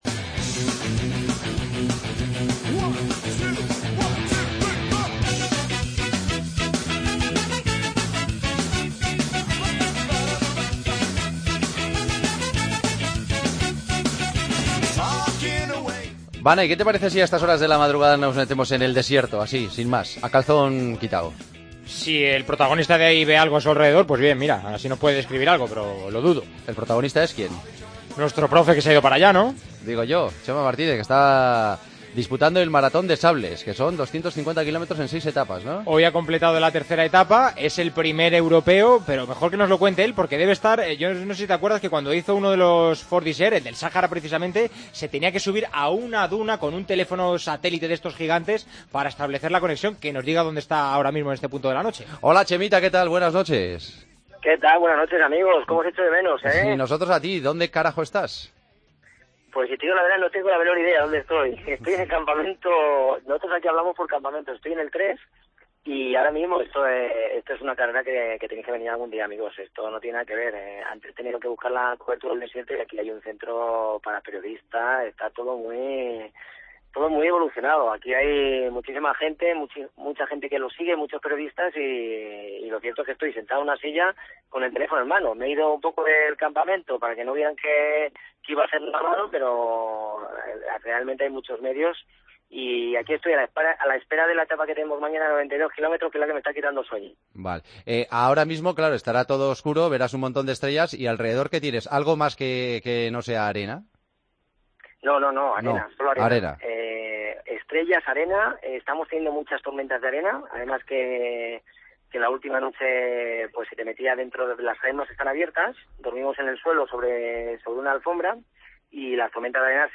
AUDIO: Esta semana, Kilómetro 42 se hace desde el Maratón de Sables, la carrera "más dura del planeta", como dice Chema Martínez.